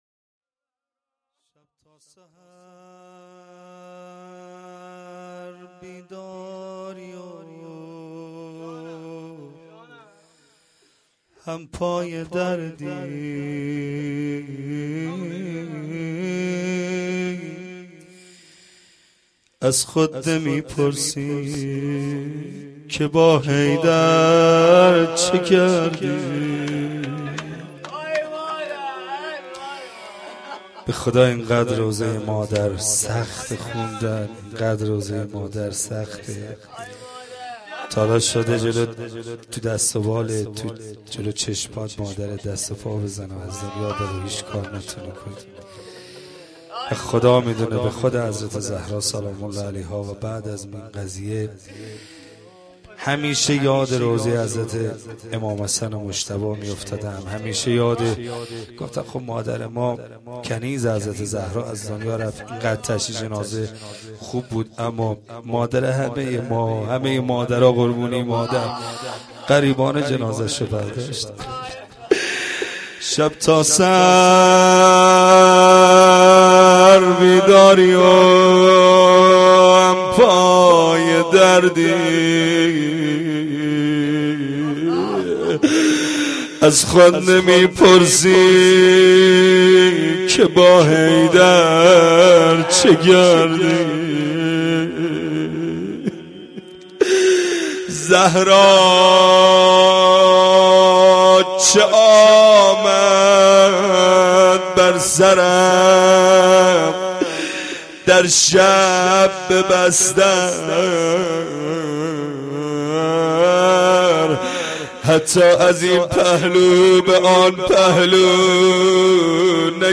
روضه2
roze2-Rozatol-abbas.Esteghbal-az-Ramezan.mp3